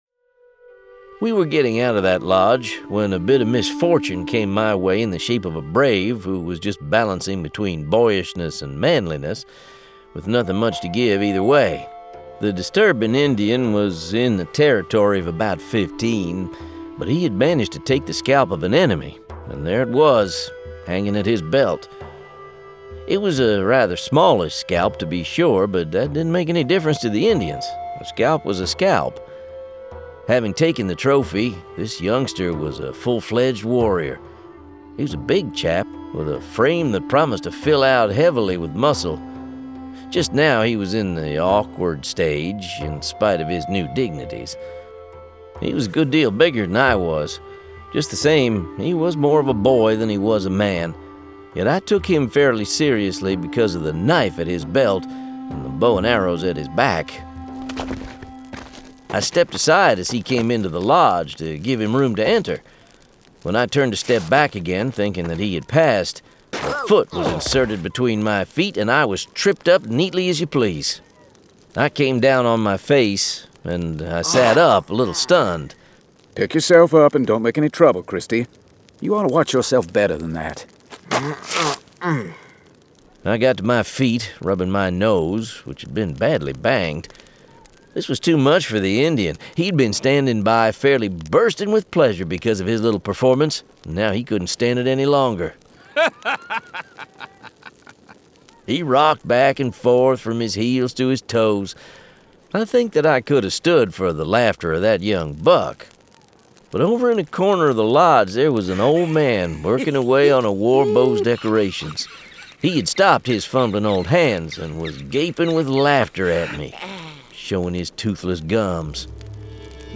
The Stone That Shines [Dramatized Adaptation]
Full Cast. Cinematic Music. Sound Effects.
Genre: Western